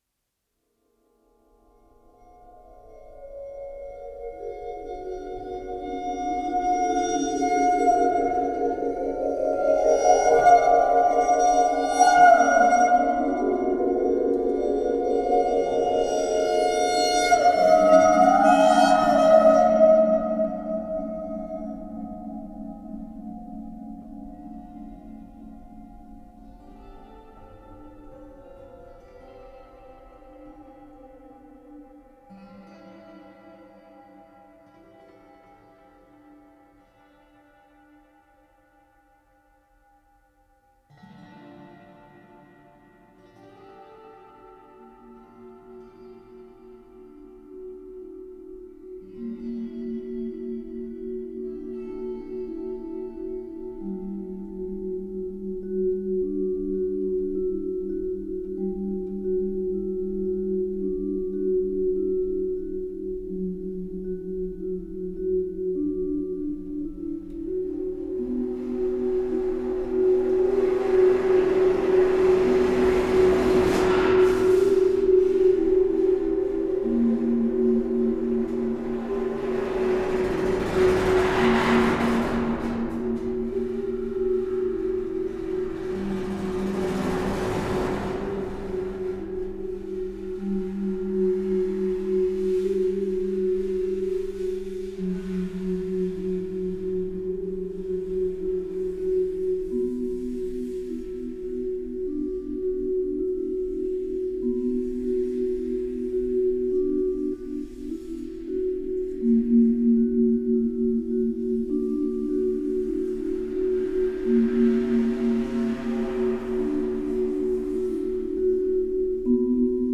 Жанр: Soundtrack.